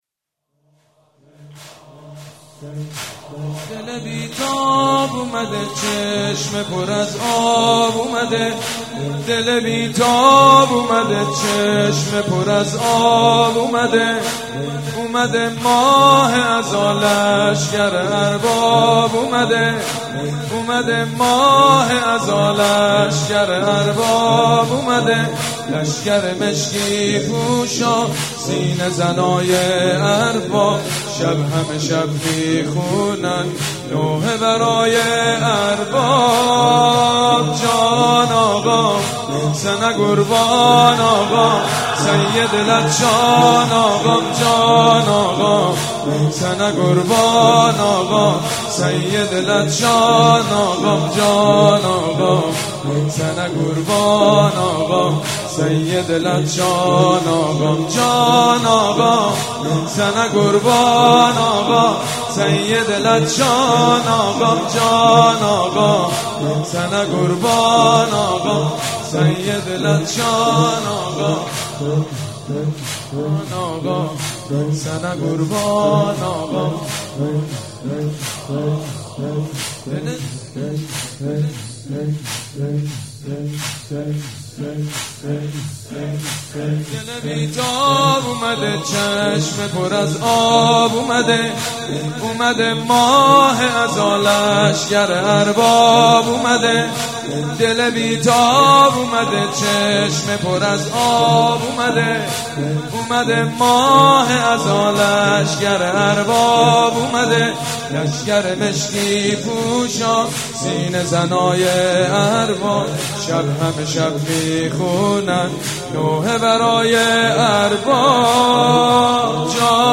مداحی مجید بنی فاطمه